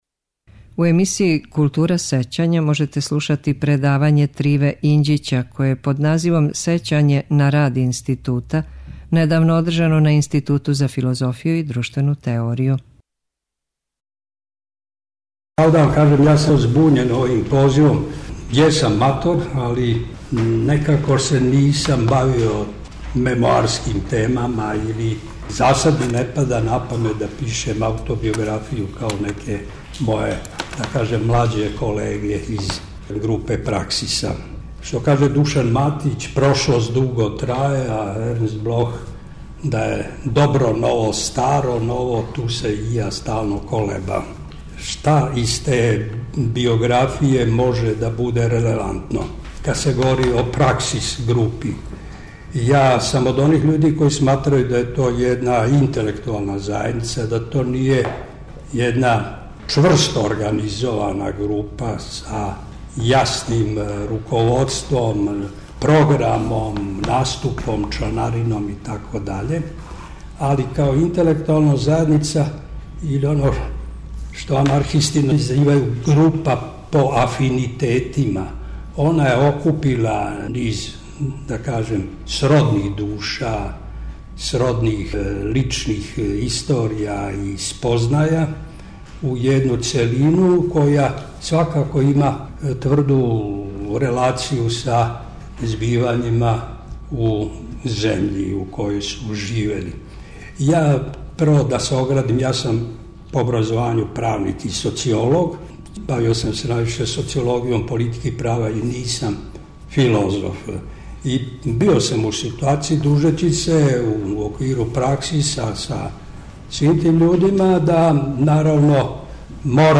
У емисији „Култура сећања”, у среду, 23. новембра, можете слушати предавање Триве Инђића које је под називом „Сећање на рад Института” недавно одржано на Институту за филозофију и друштвену теорију у Београду.